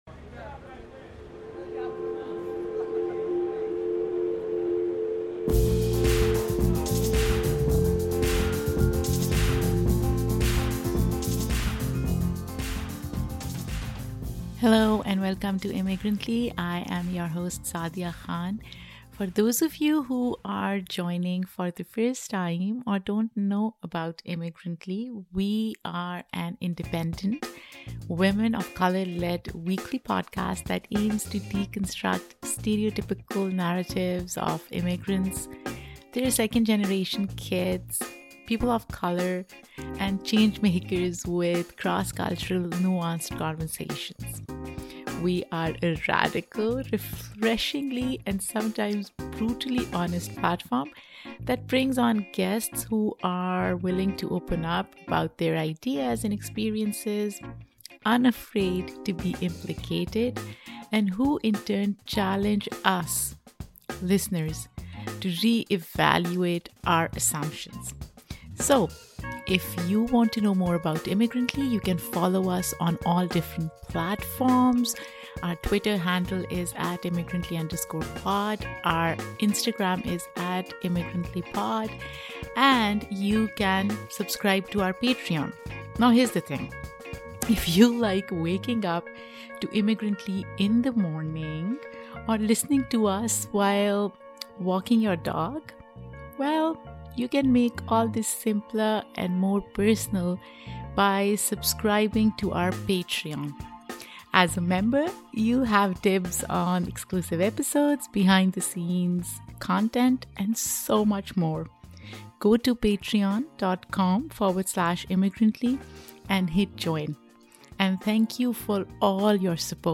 joins us in the studio to discuss her work, a product of what is personal and public.